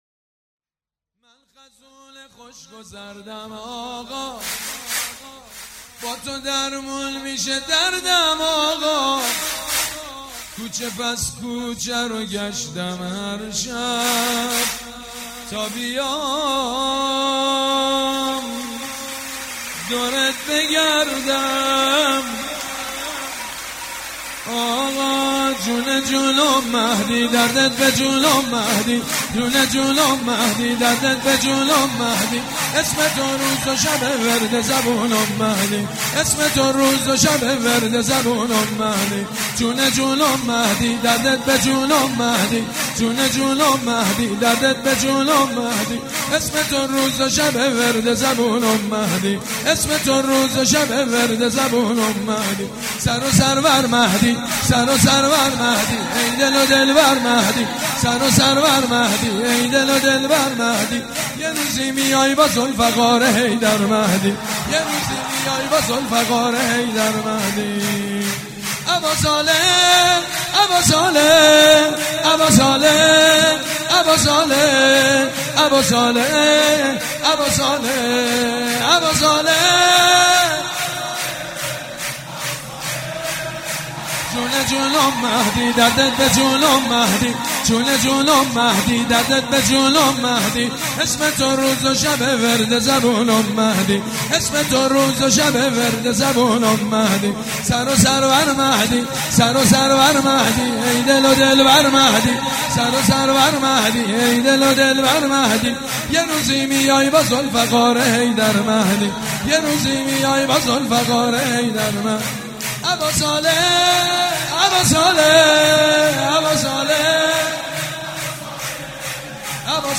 سبک اثــر سرود مداح حاج سید مجید بنی فاطمه
جشن نیمه شعبان
سرود3.mp3